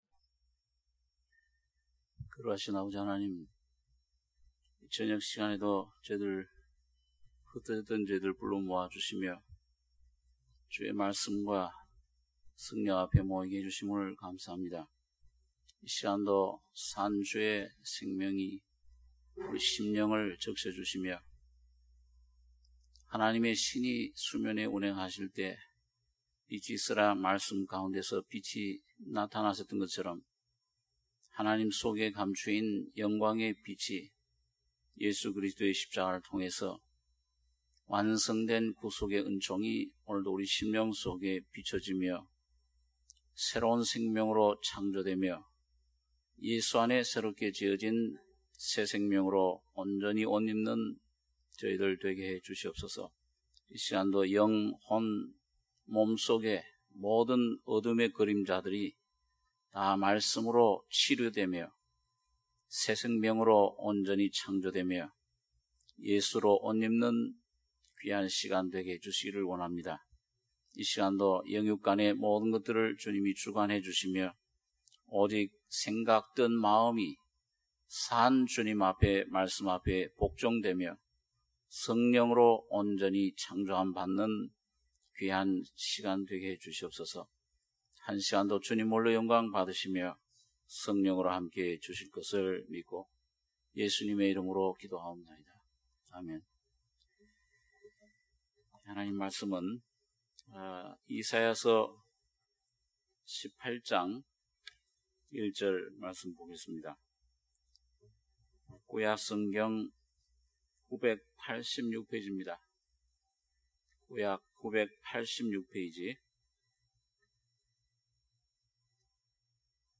수요예배 - 이사야 18장 1절-7절